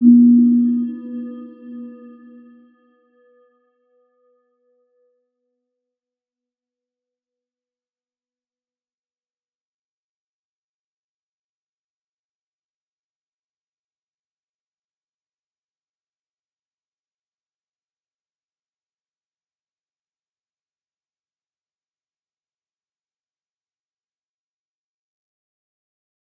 Round-Bell-B3-mf.wav